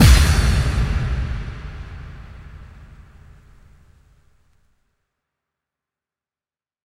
BWB UPGRADE3 FX HIT (10).wav